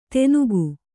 ♪ tenugu